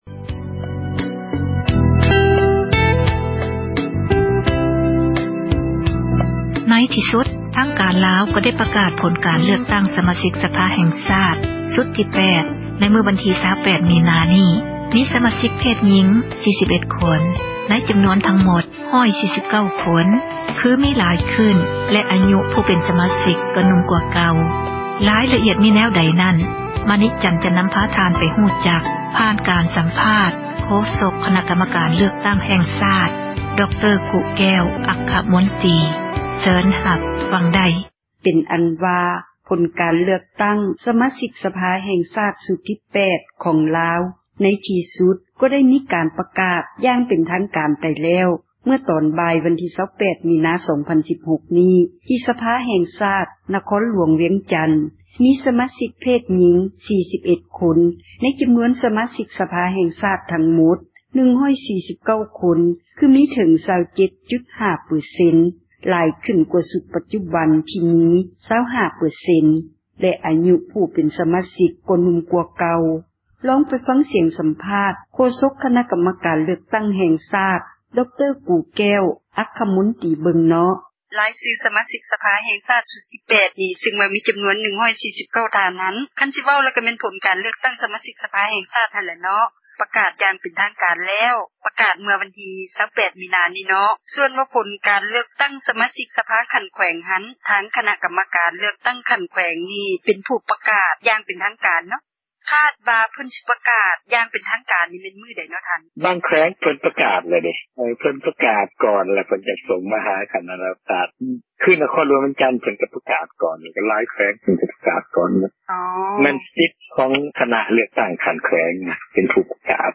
ລອງໄປຟັງ ການສັມພາດ ໂຄສົກ ຄນະ ກັມການ ເລືອກຕັ້ງ ແຫ່ງຊາດ ດຣ. ກູ່ແກ້ວ ອັກຄະມົນຕີ ເບິ່ງ.